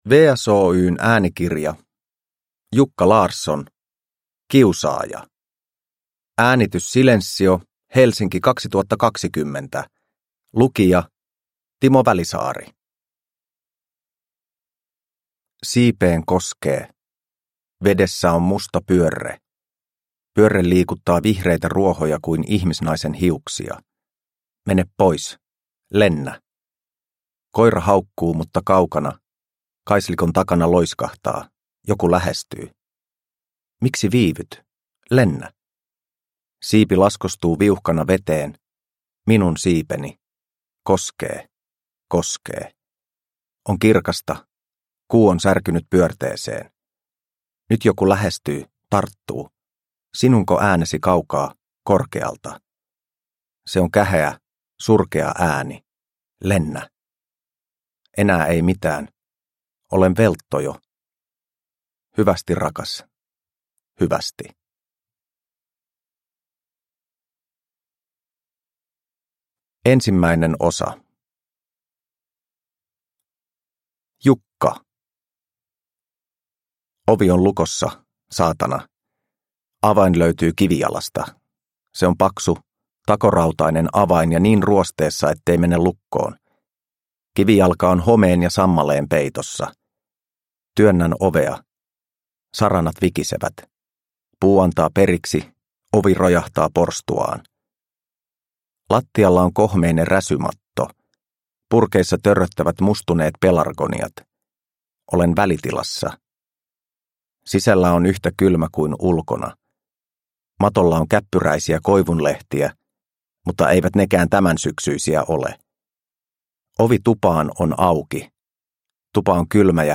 Kiusaaja – Ljudbok – Laddas ner